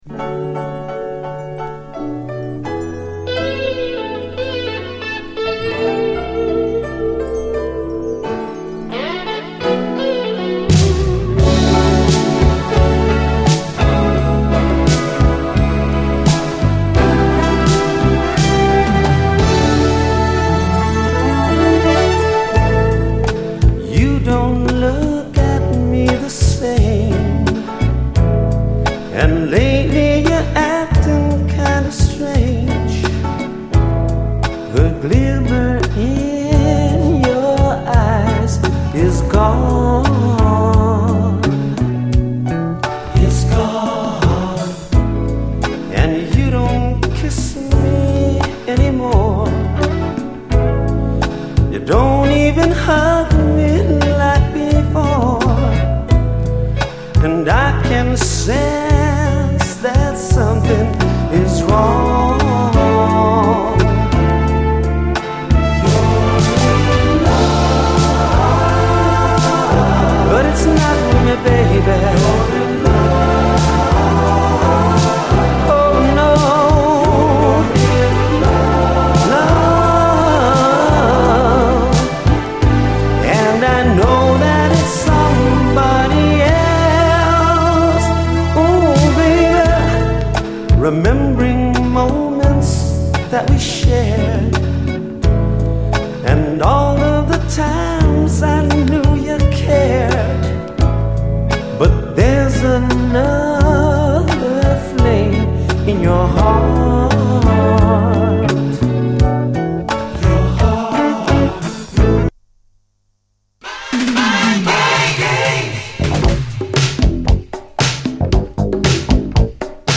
後半はダブに接続。